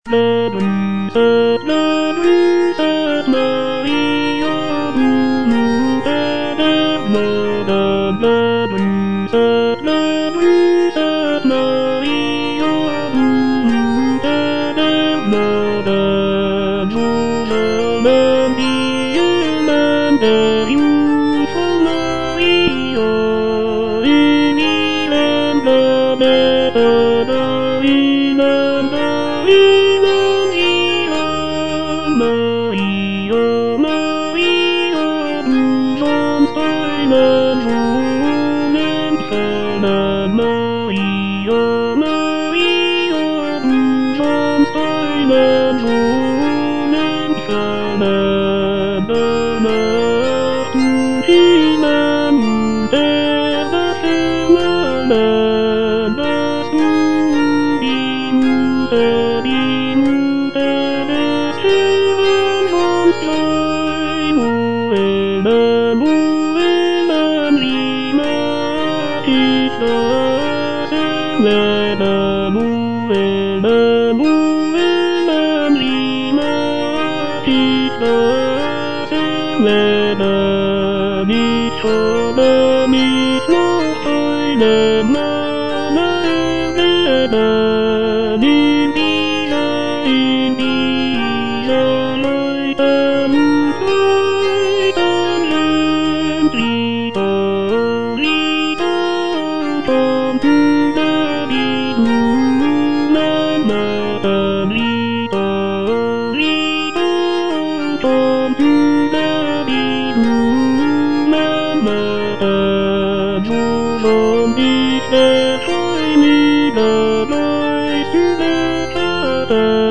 J. BRAHMS - DER ENGLISCHE GRUSS OP. 22 NO. 1 Tenor (Voice with metronome) Ads stop:
The piece is written for a four-part mixed choir and piano accompaniment.